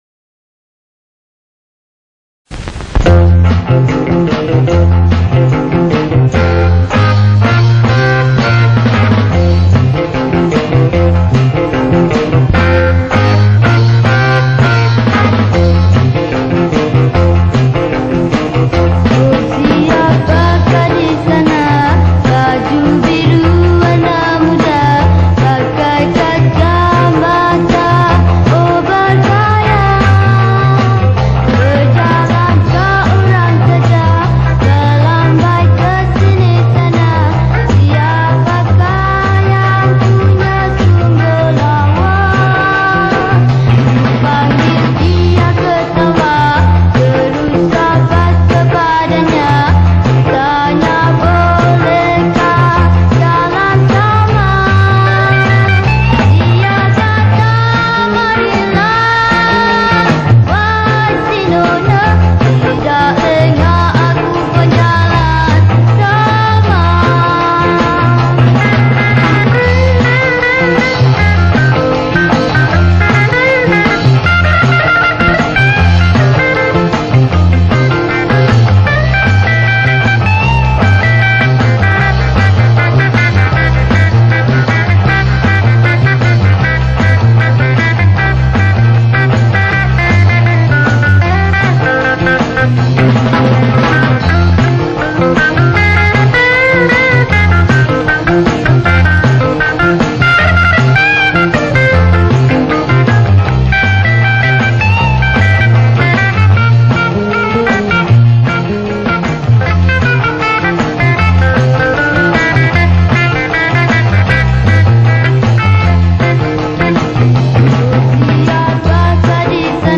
Malay Songs , Pop Yeh Yeh